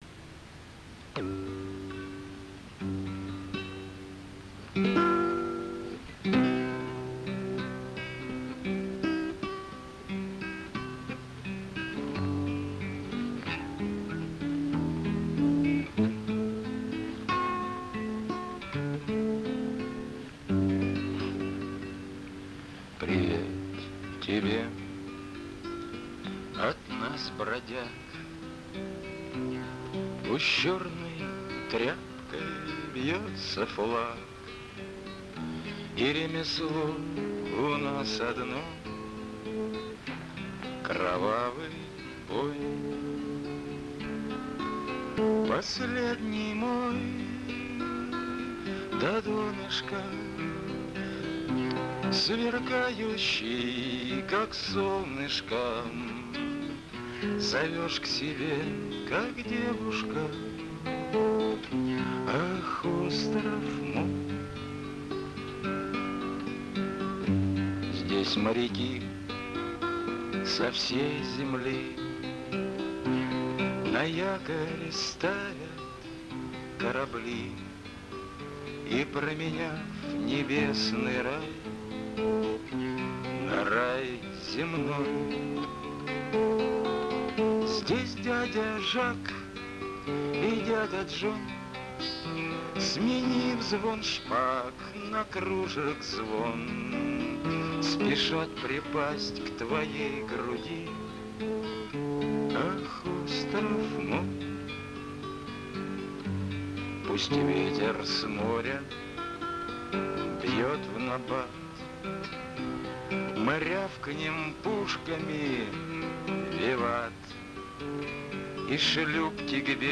музыка, слова и исполнение